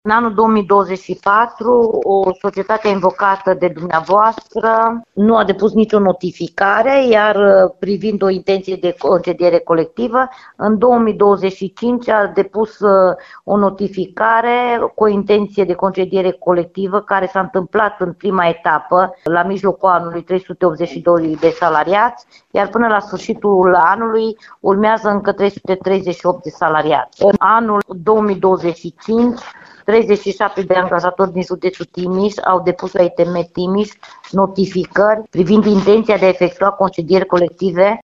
La solicitarea Radio Timișoara, șefa Inspectoratului Teritorial de Muncă Timiș, Ileana Mogoșanu, a precizat că instituția a primit inițial o notificare de concediere colectivă pentru 720 de persoane, în două etape.